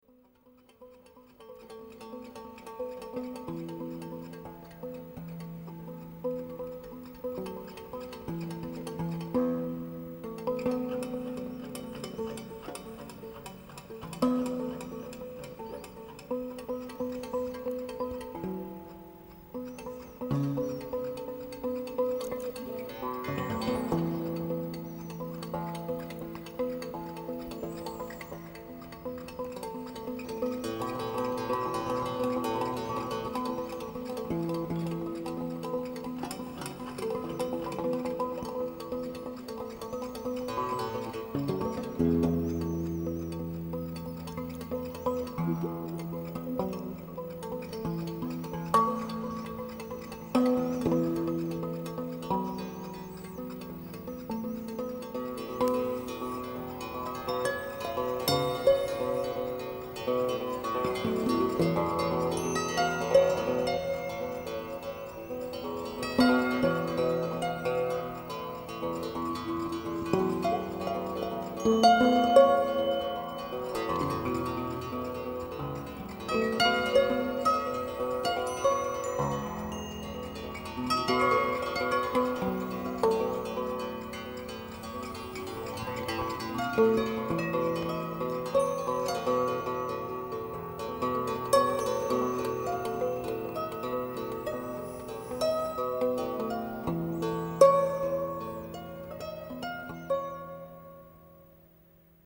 A lush musical painting of the Australian landscape.